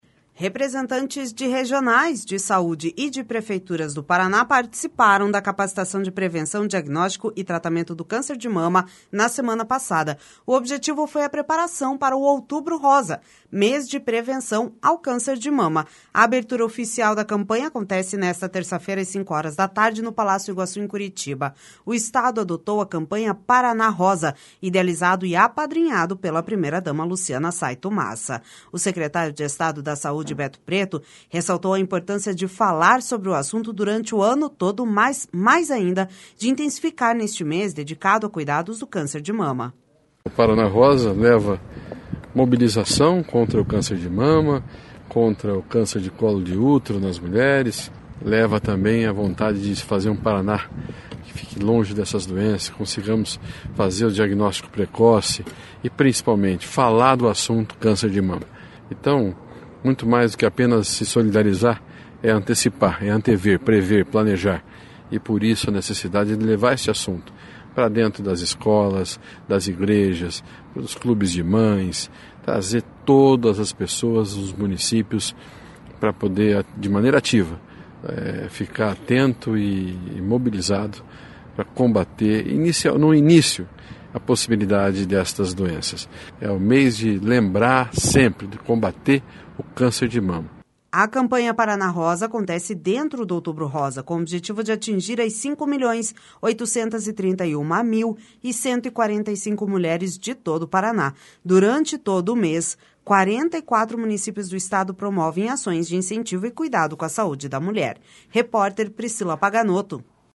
O secretário de Estado da Saúde, Beto Preto, ressaltou a importância de falar sobre o assunto durante o ano todo, mas mais ainda de intensificar nesse mês, dedicado aos cuidados do câncer de mama.// SONORA BETO PRETO//